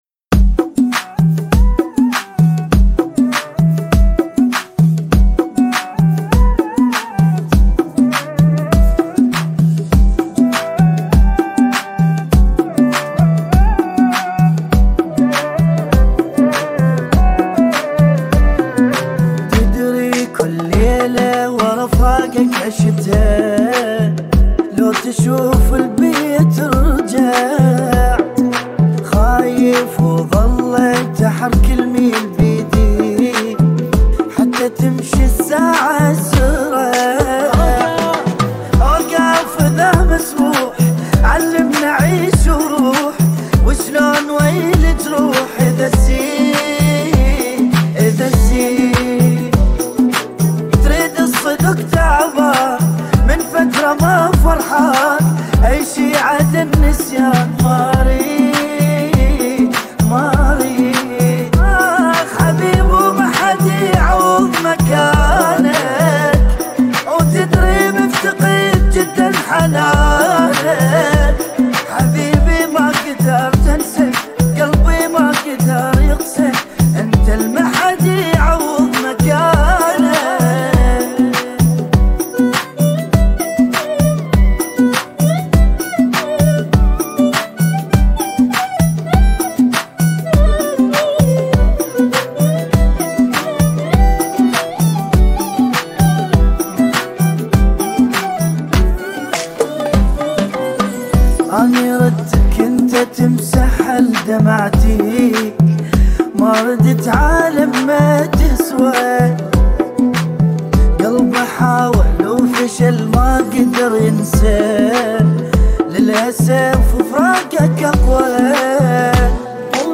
100 bpm
بدون جنقل